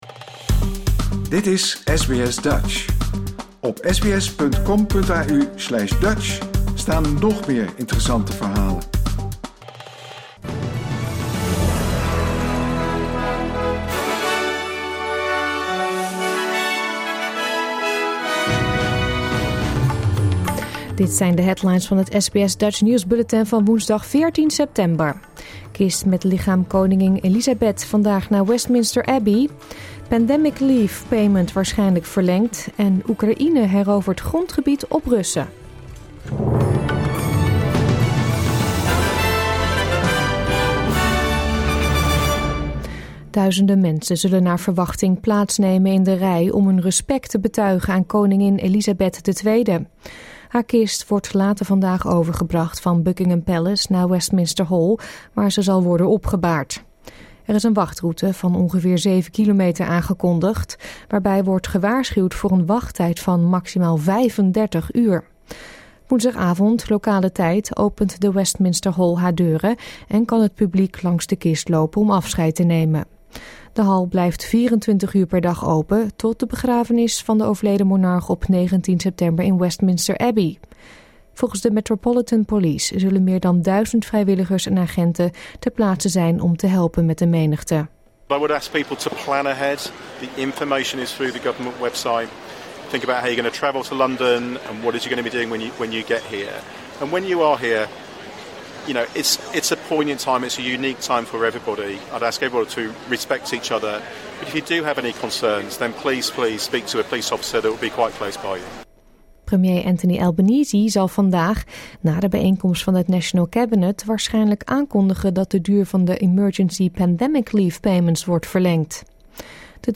Nederlands / Australisch SBS Dutch nieuwsbulletin van woensdag 14 september 2022